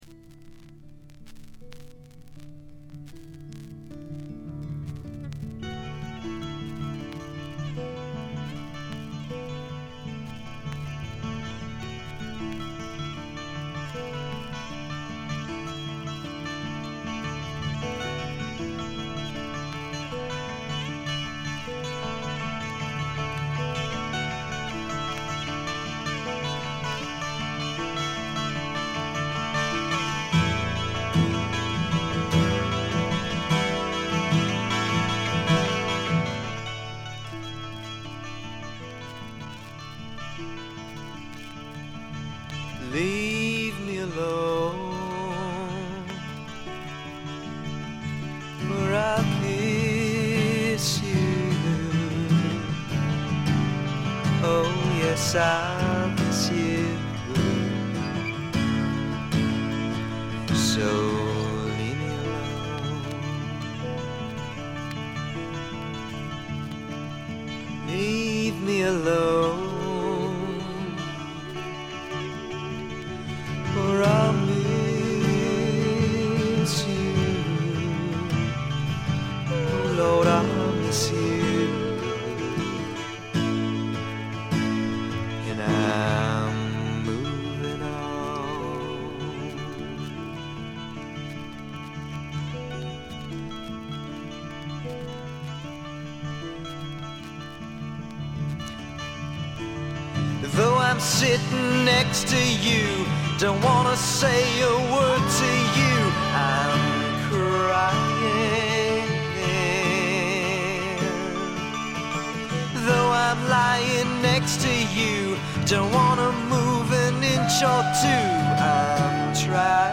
見た目に反して、全体にバックグラウンドノイズ、チリプチが多め。
基本は英国的としか言いようのない重厚で深い陰影のある哀愁のフォークロックです。
試聴曲は現品からの取り込み音源です。